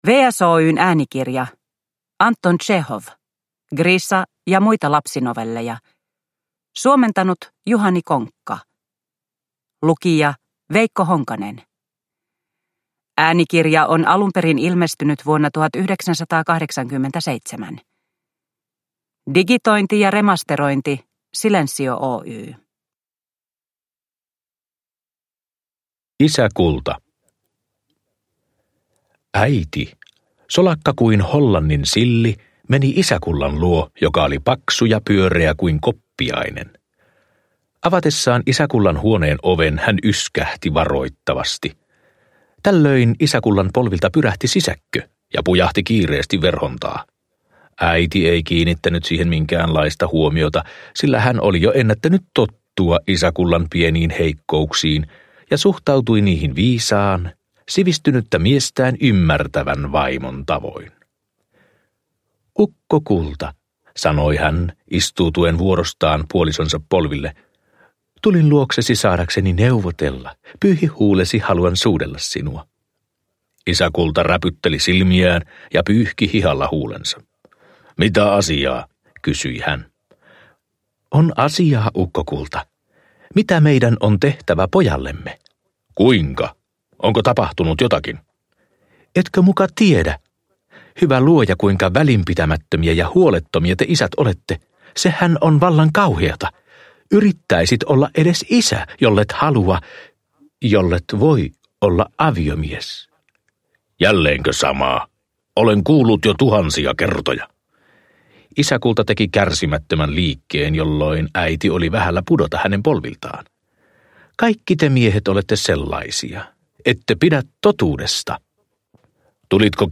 Griša ja muita lapsinovelleja – Ljudbok – Laddas ner